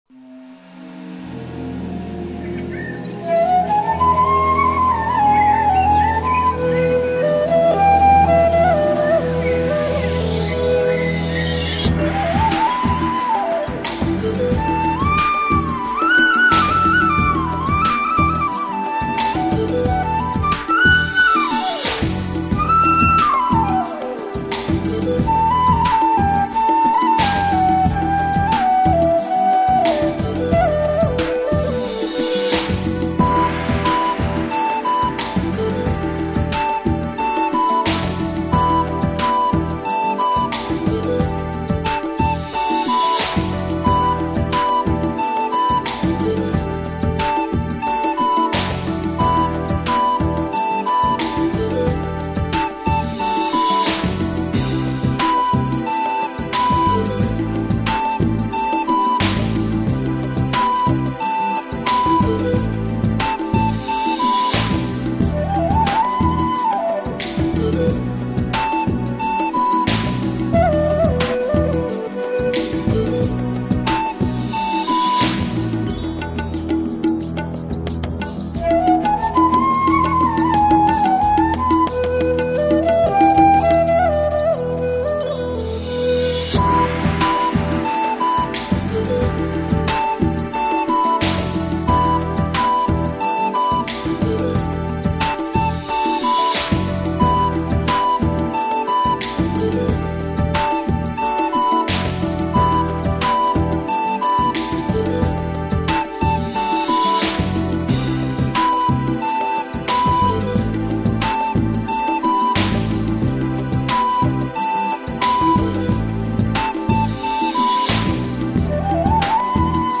Comencem amb la flauta!